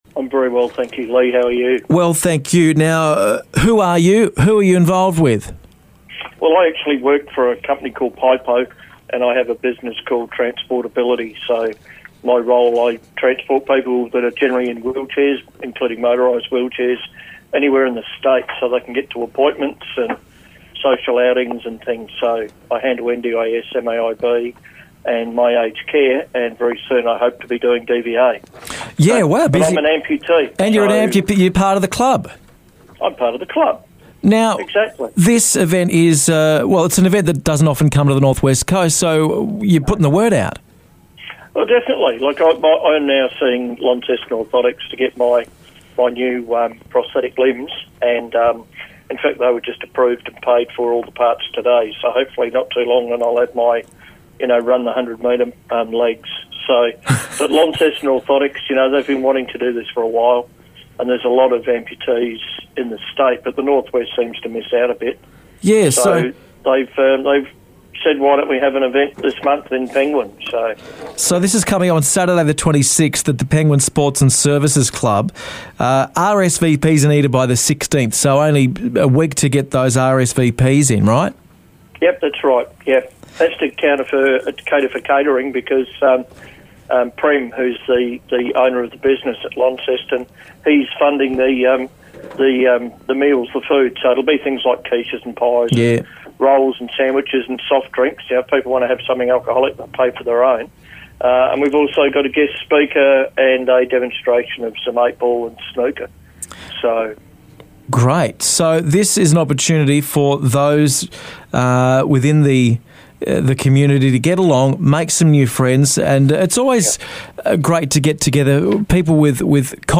called in for a chat about the event.